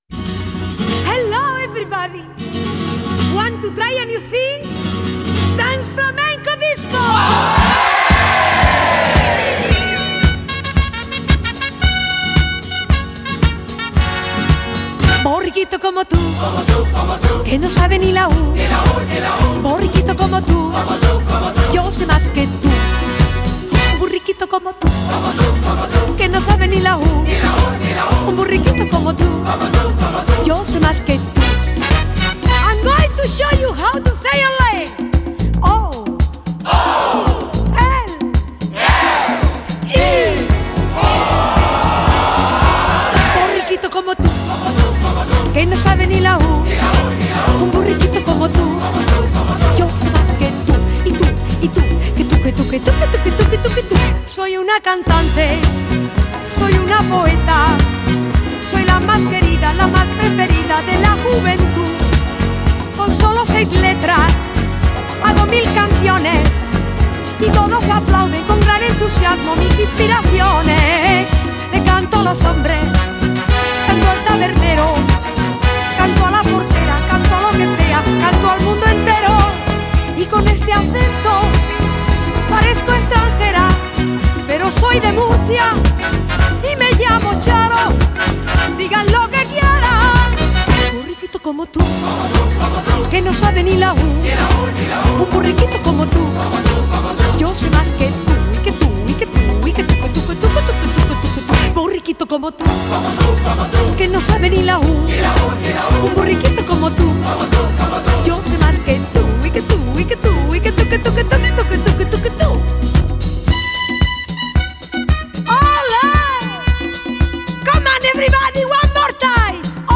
Genre: Funk / Soul, Disco